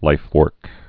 (līfwûrk)